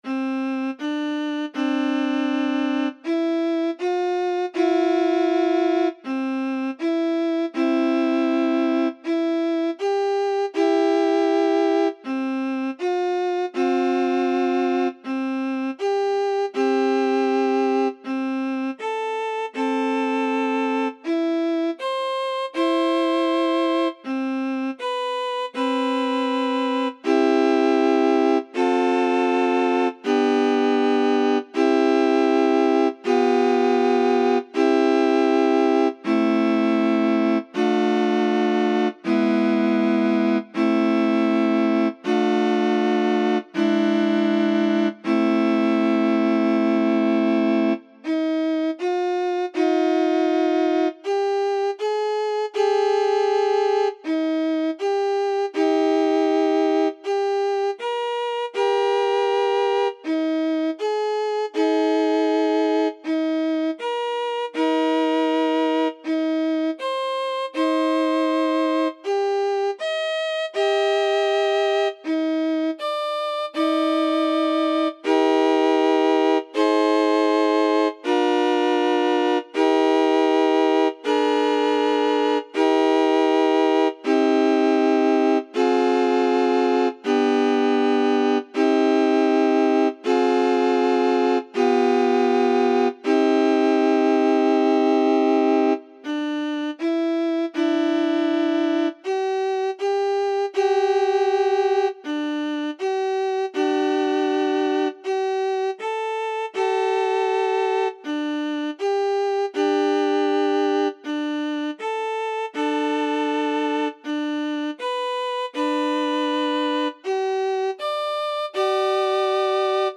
純正律は「基準となる音」は「A」、「主音」は「自動」としました。
純正律
音色はバイオリンです。ノンビブラートにはしませんでしたので、確かに多少ビブラートは入っていると思います。それよりうなりの方が強いようです。
純正律.mp3